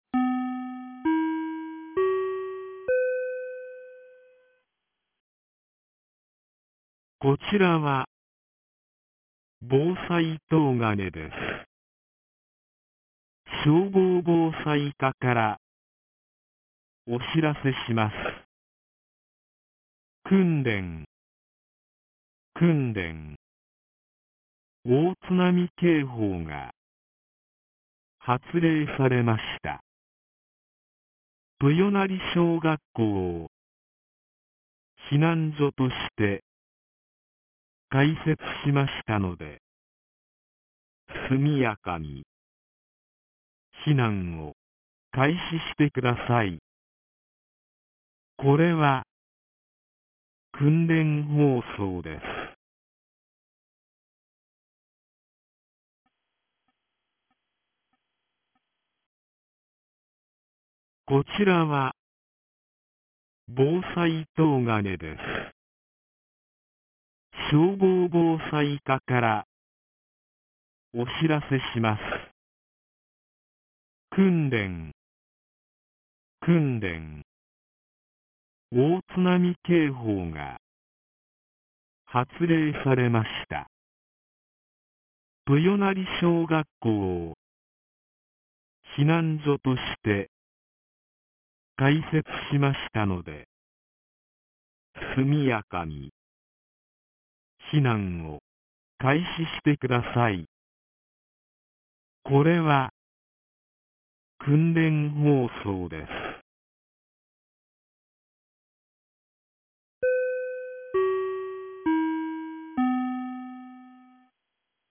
東金市放送内容 2025年11月01日09時17分 【訓練放送】避難所開設 | 千葉県東金市メール配信サービス
2025年11月01日 09時17分に、東金市より防災行政無線の放送を行いました。